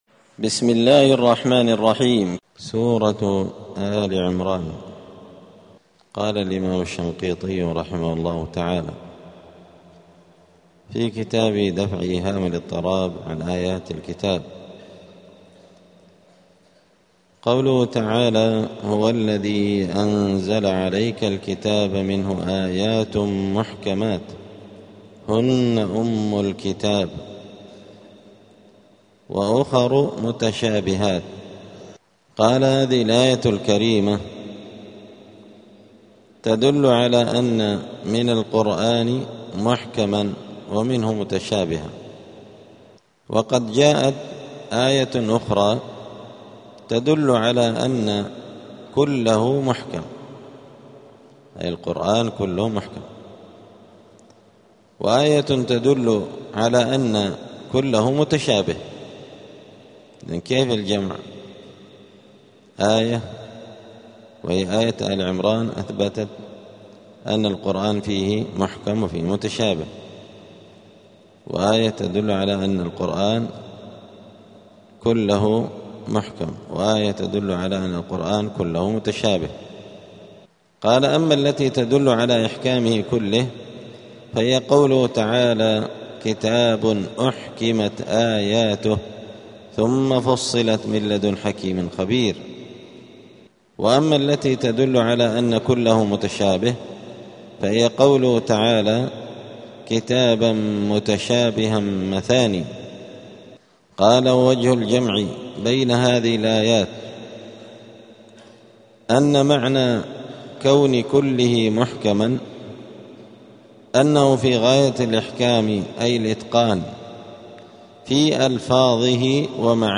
*الدرس الرابع عشر (14) {سورة آل عمران}.*